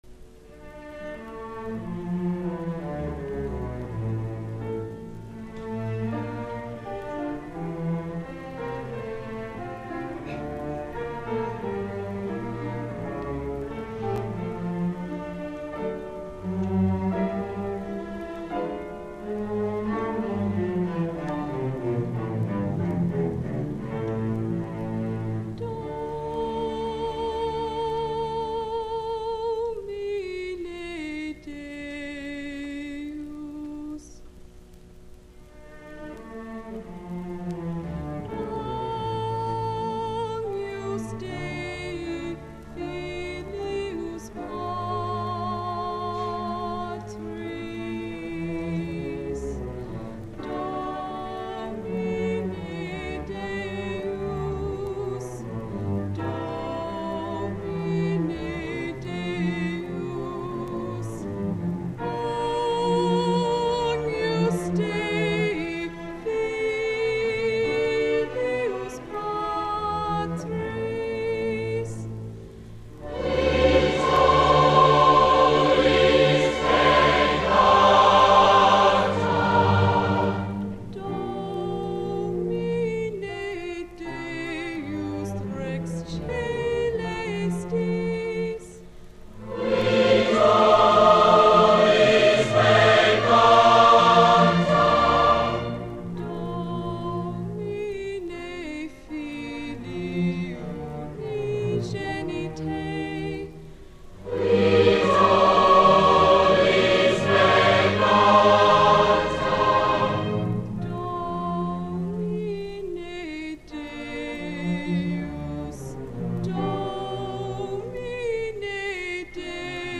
Recordings of public performances of the Music Department
contralto